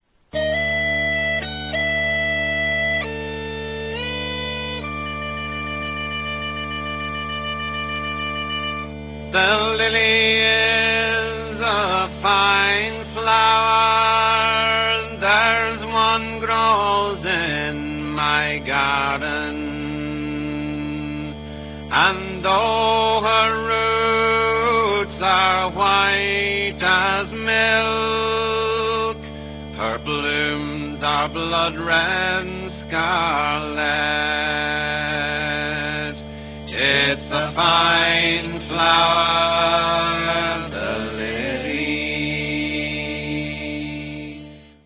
The appropriately plain storytelling style here